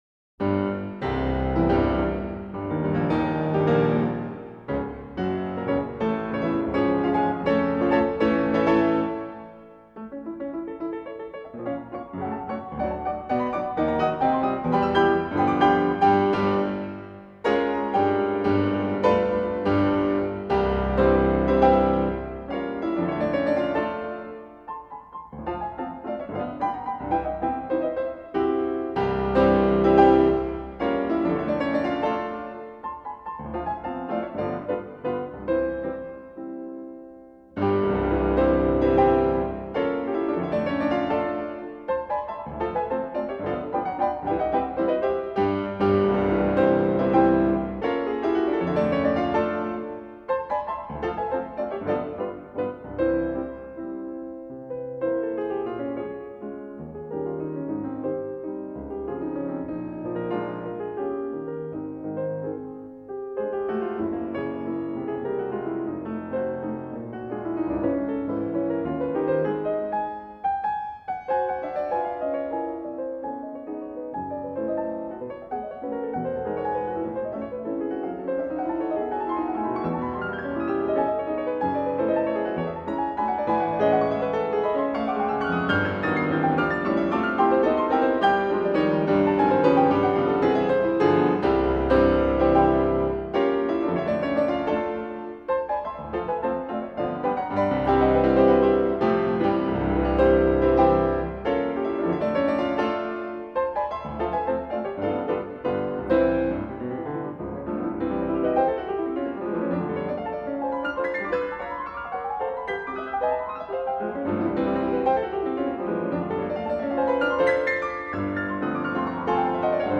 piano
正攻法でスクウェアにピアノ演奏に対した感じの録音。オーソドックスであり、妙な作為を感じさせない安定感があ る。
近接的収録でのキンキン、カンカンしたピアノ・サウンドになり がちなところがなく、ナチュラルかつクリアな収録である。